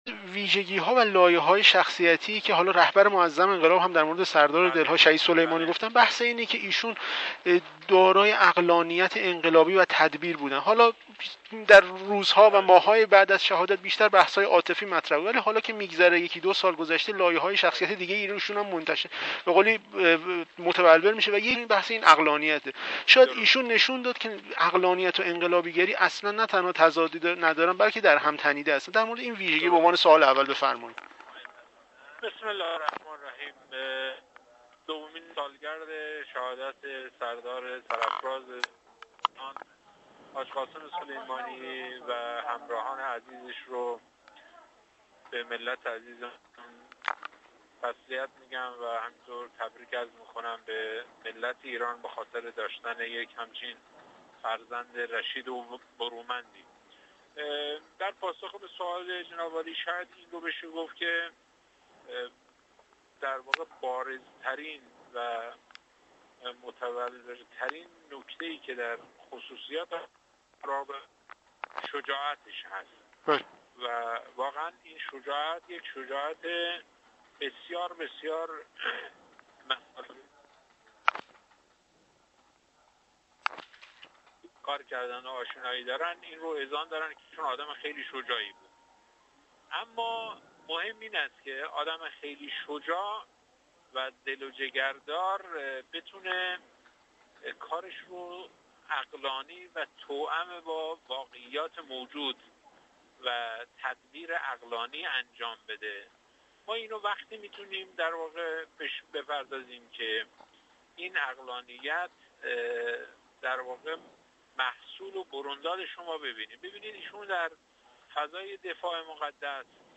گفتگو با سردار رمضان شریف